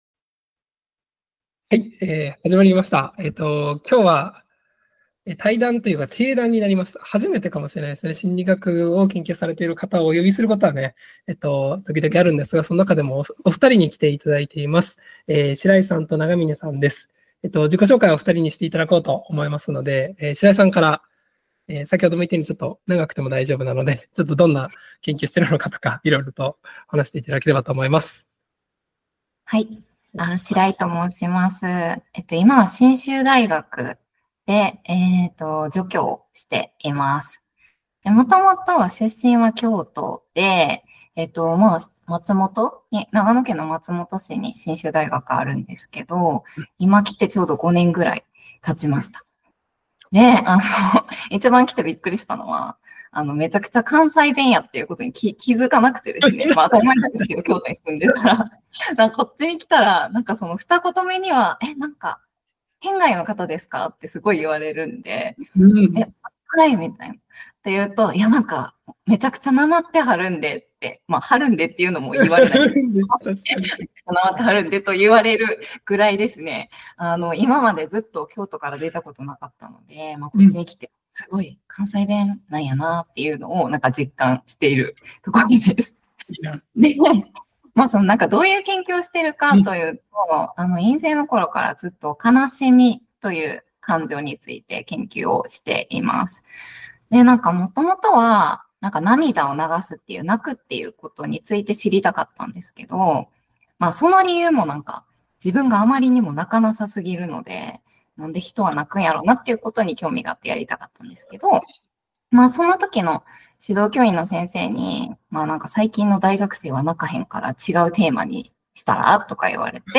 Audio Channels: 2 (stereo)
心理学対談